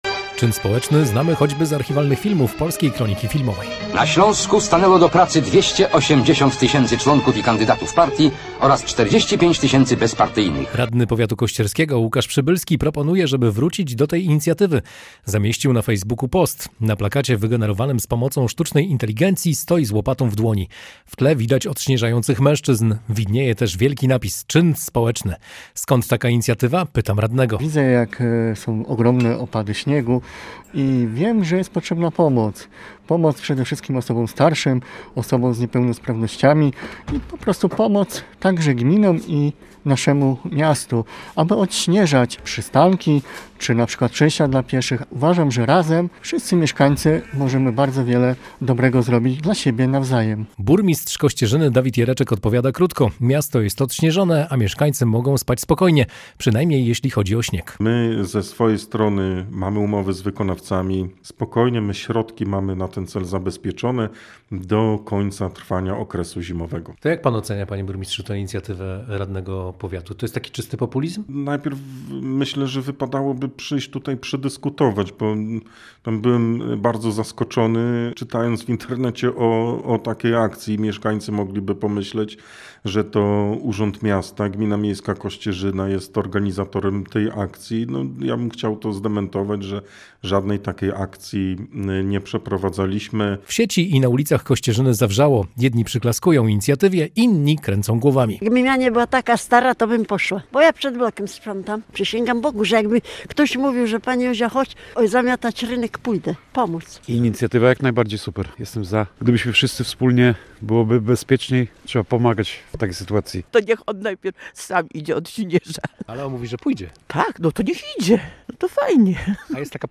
Posłuchaj materiału reportera: https